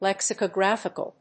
音節lèx・i・co・gráph・i・cal 発音記号・読み方
/‐fɪk(ə)l(米国英語)/